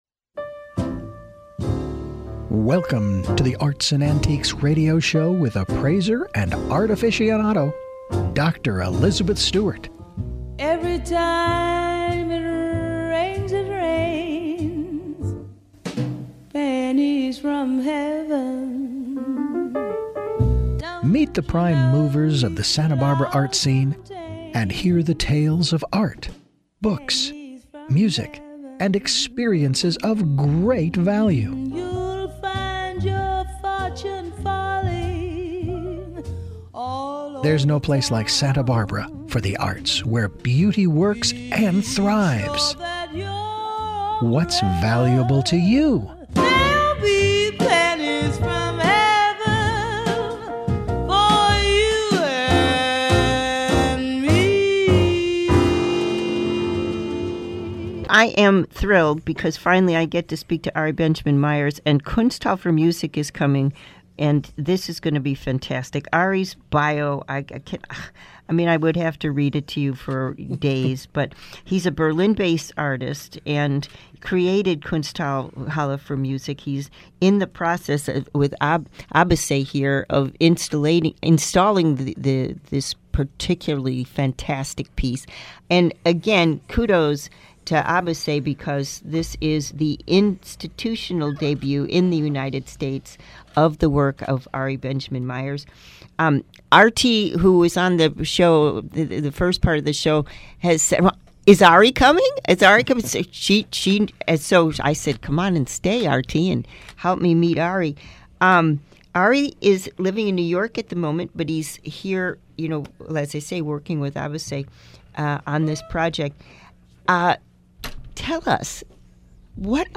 KZSB Interview